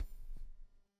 Soccer Kick
A powerful soccer ball kick with leather impact, air compression, and brief flight whoosh
soccer-kick.mp3